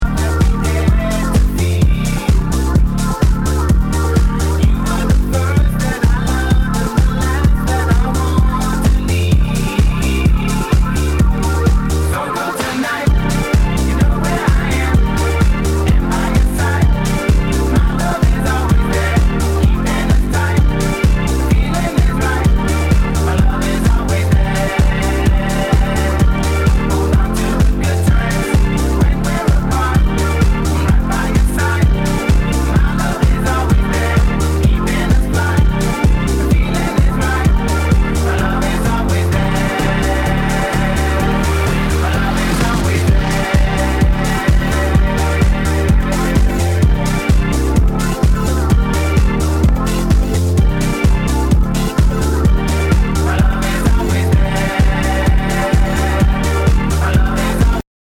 HOUSE/TECHNO/ELECTRO
ナイス！ヴォーカル・ハウス!!